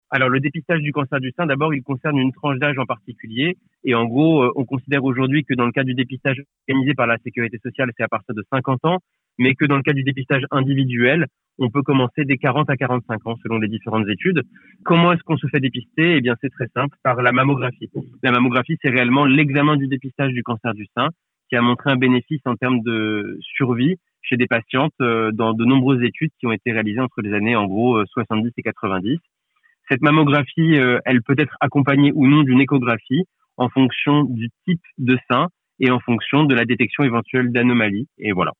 radiologue et sénologue dans un hôpital